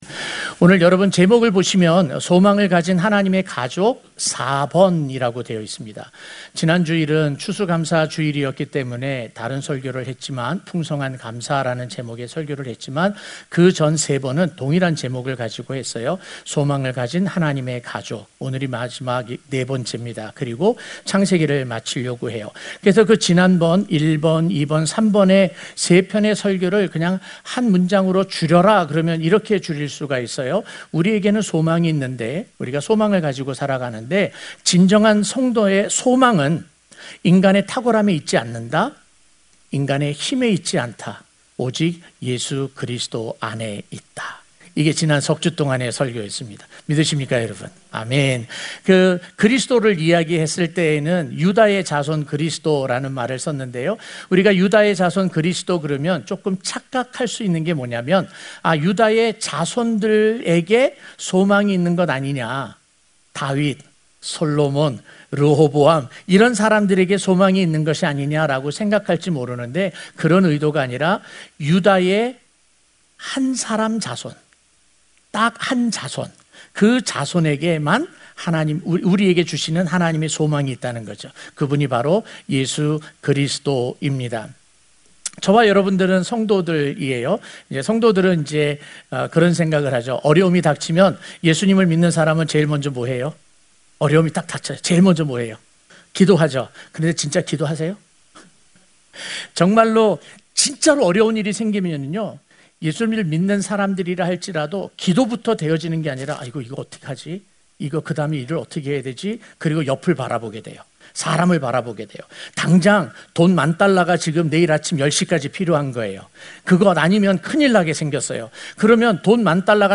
주일설교